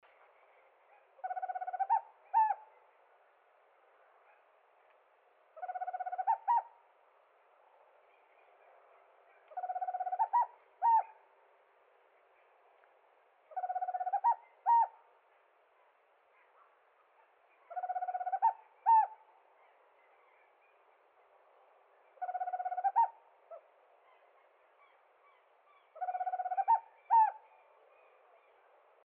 Corujinha-do-mato (Megascops choliba)
Nome em Inglês: Tropical Screech Owl
Localidade ou área protegida: Dique Los Alisos
Condição: Selvagem
Certeza: Gravado Vocal